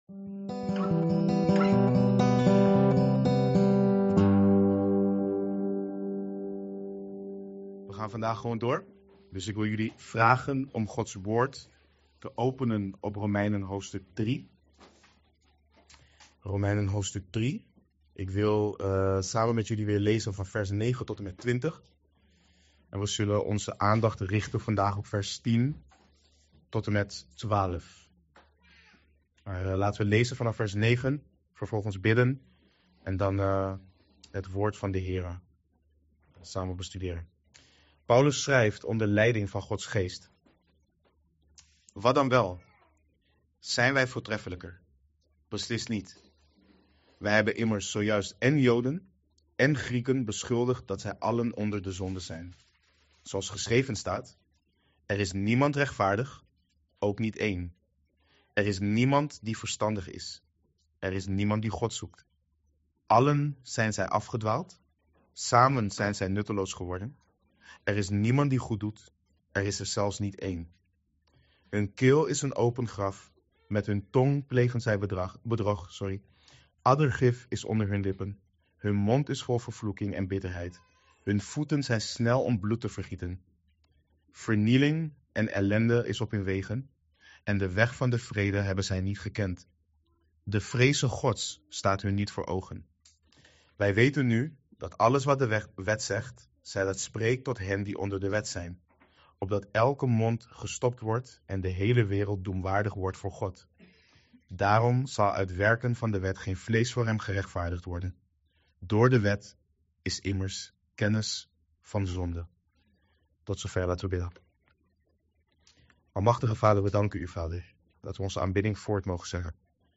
Verklarende prediking.